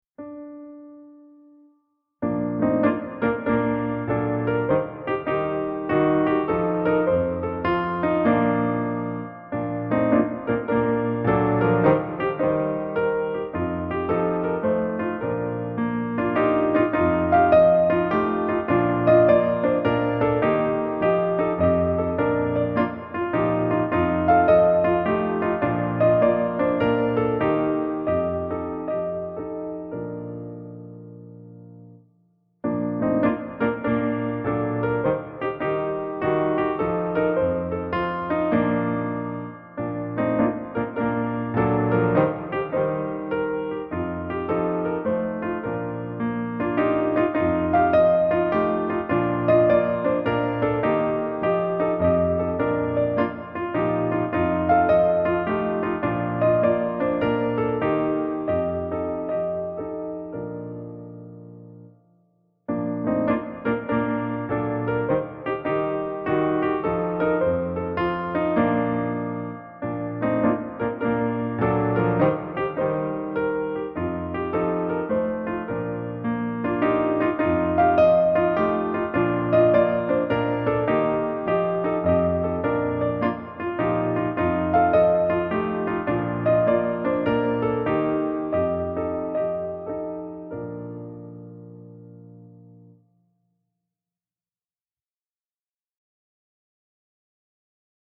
223-EF-Gesang_in_der_Natur.mp3